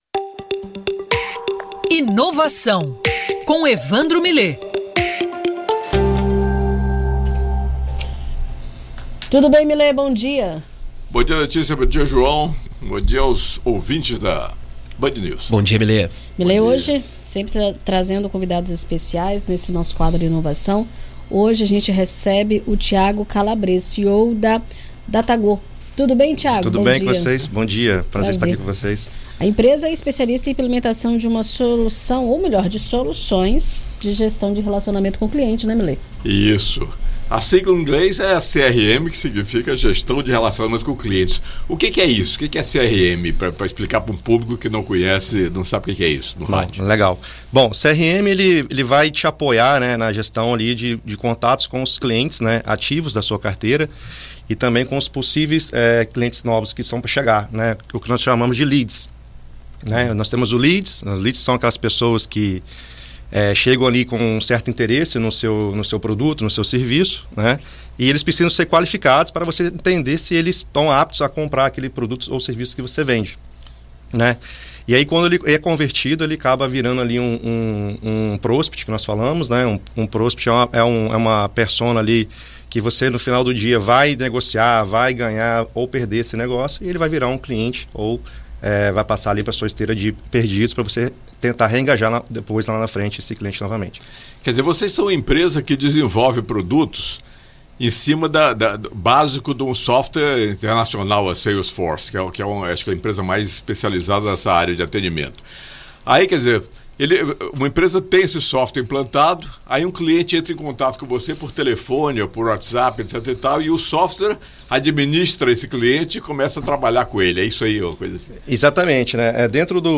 Na coluna Inovação desta terça-feira (04), na BandNews FM Espírito Santo